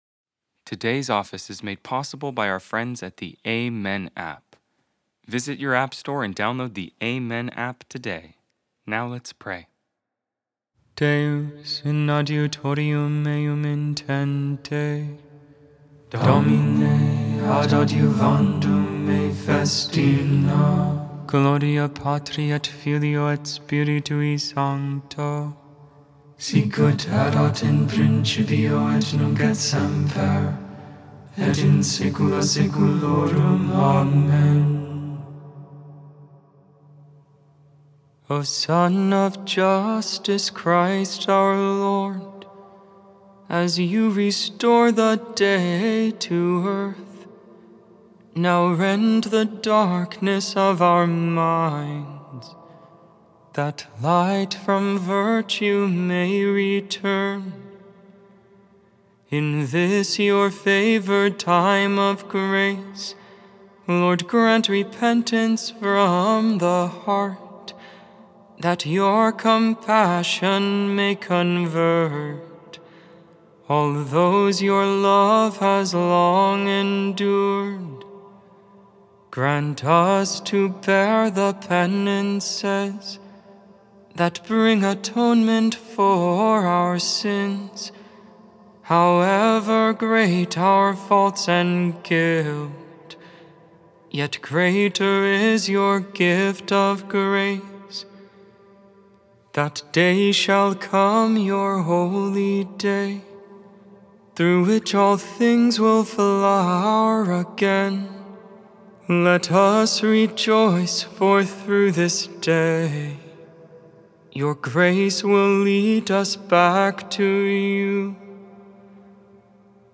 The Liturgy of the Hours: Sing the Hours